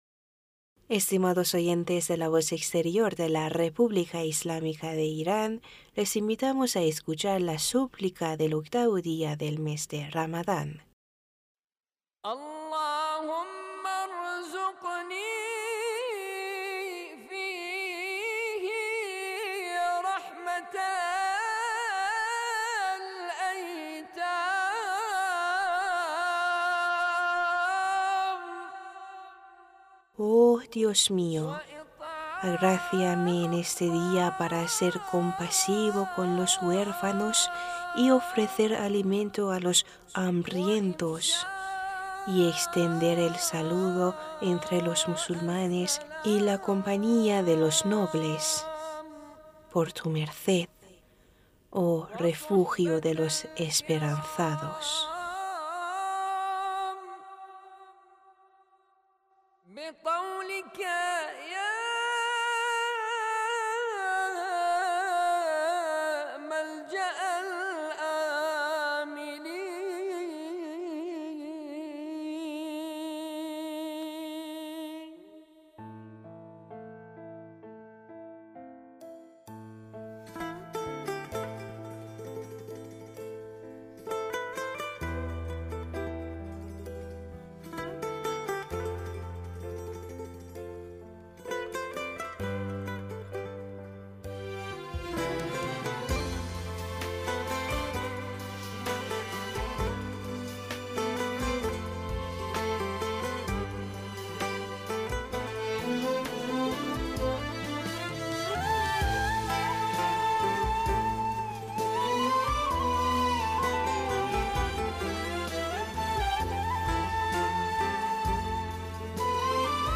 Súplica de 8vo.Día de Ramadán